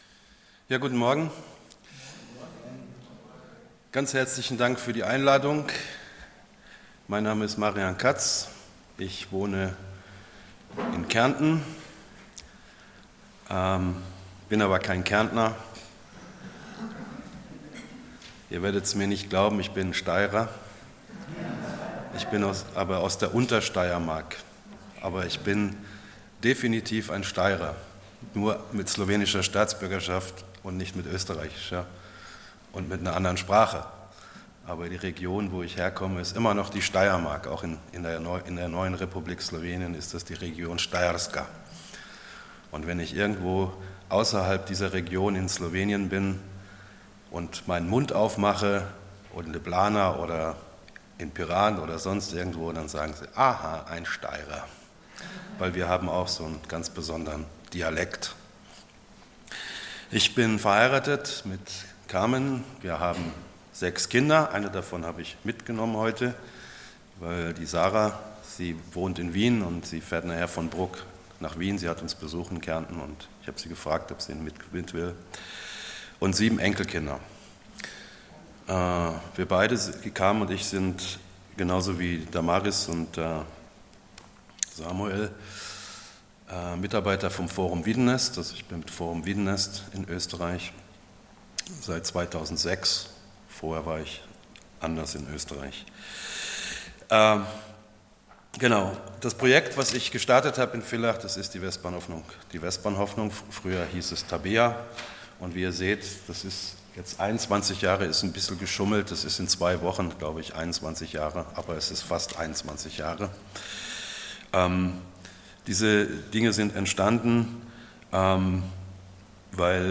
Serie: Allgemeine Predigten Passage: Psalm 62:1-13 Dienstart: Sonntag Morgen